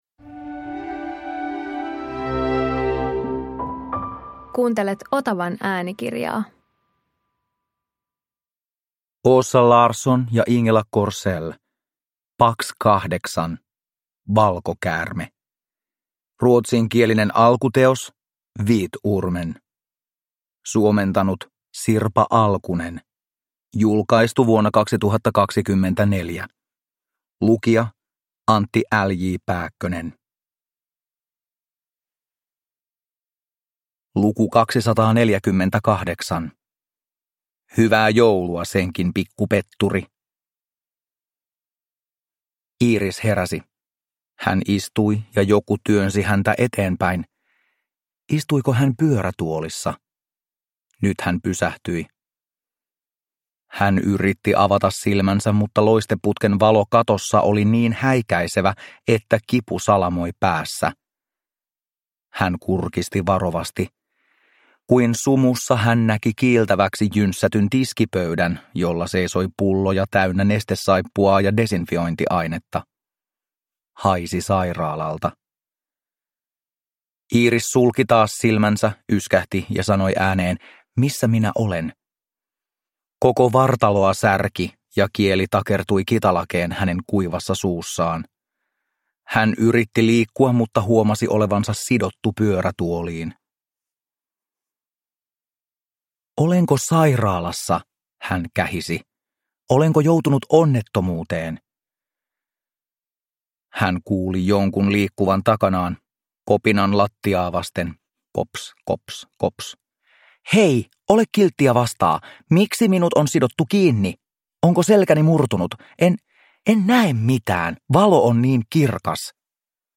Pax 8 - Valkokäärme (ljudbok) av Åsa Larsson